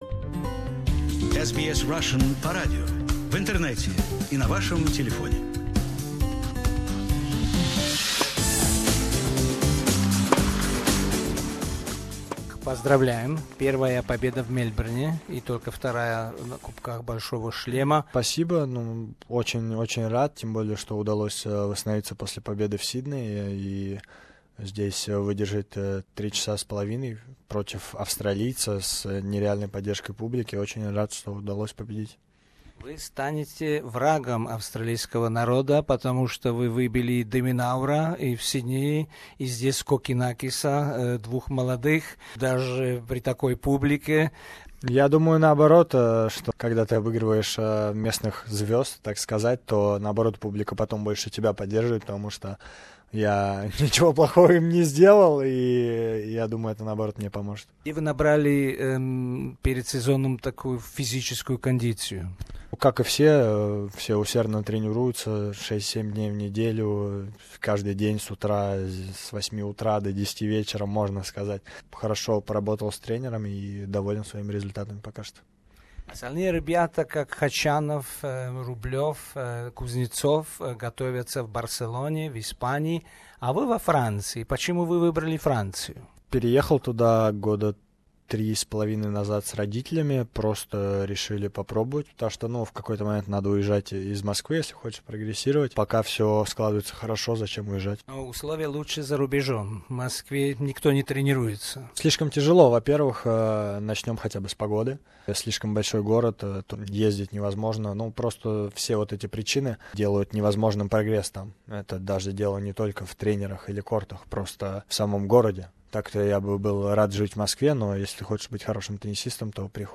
On the second day of Australian Open Russian Daniil Medvedev has defeated Australian Thanasi Kokkinakis 6:2, 6:7 (6), 7:6 (8), 6:4 in 3 hours 29 minutes. We congratulated Daniil on his first victory asked him a few questions on his training in France and future prospects.